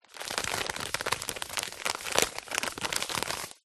На этой странице собраны натуральные звуки семечек: от раскалывания скорлупы зубами до шуршания шелухи.
Звук открытия упаковки с семечками